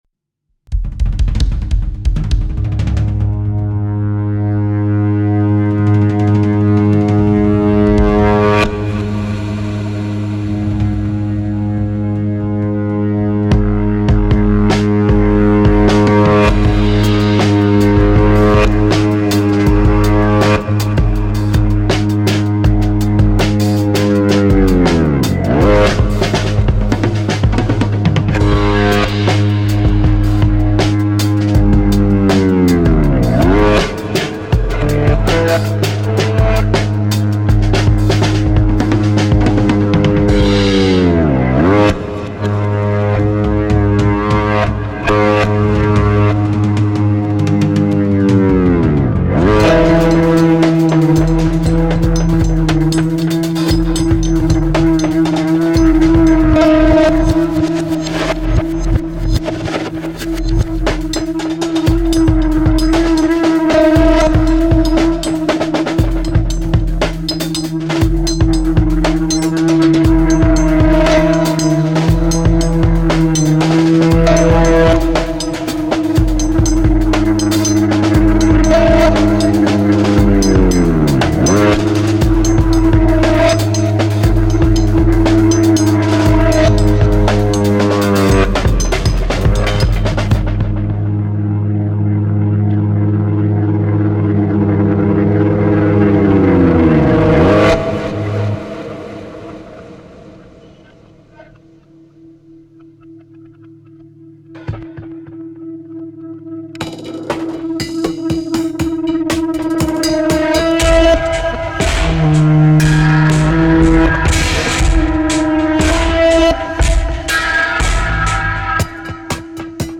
Pipa Desert Rock mega mix in 4 parts